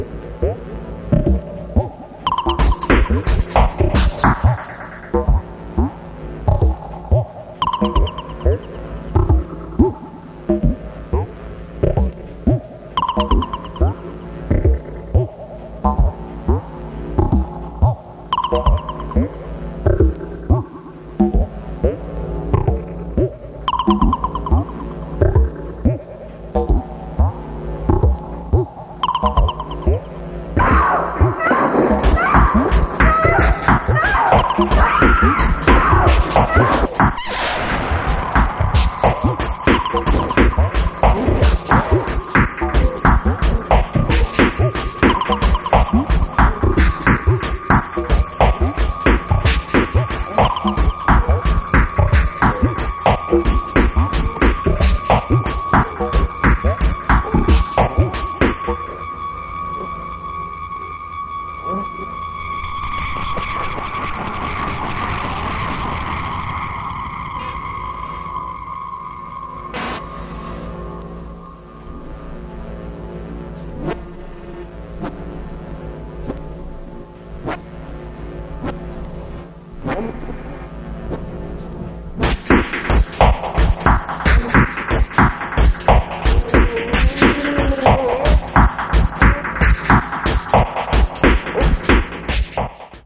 とにかく、豊富なアイデアを垂れ流すようにノイズし続けた本シリーズのエンディングに相応しい素晴らしい内容です！